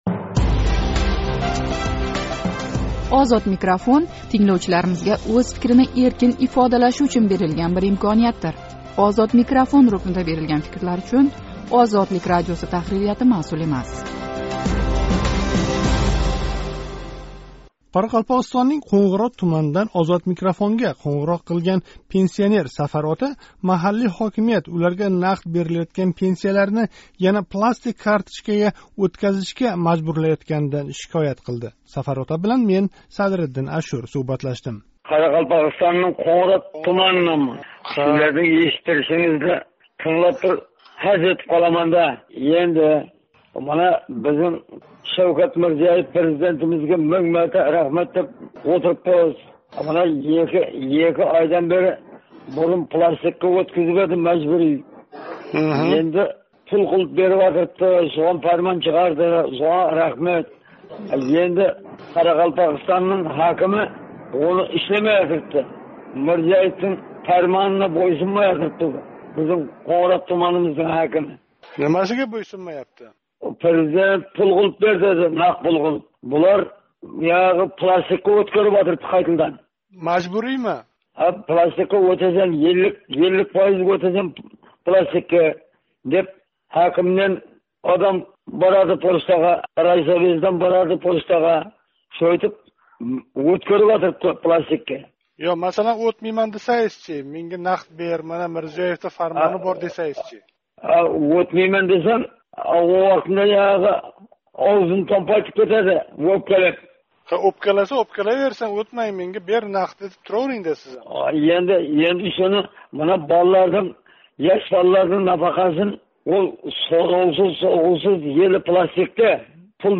суҳбат: